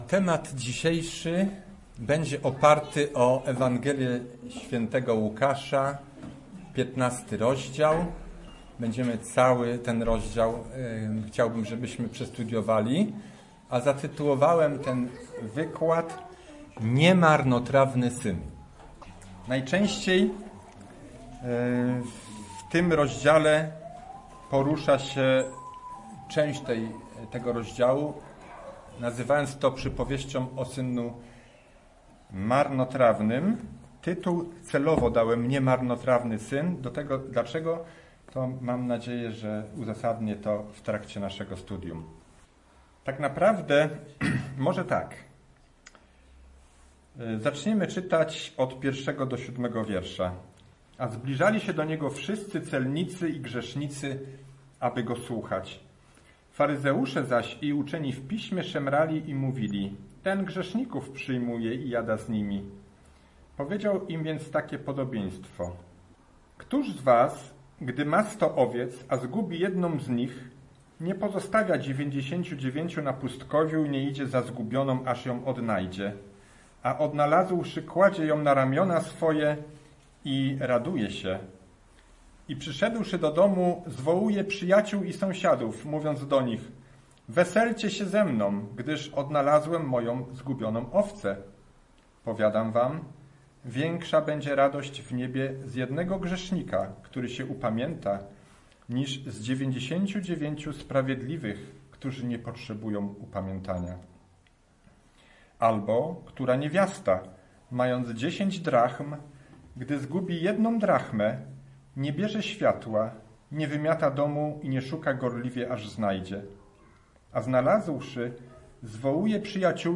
Kazanie o synu marnotrawnym - przypowieść - powrót do Boga mp3 - CHS Poznań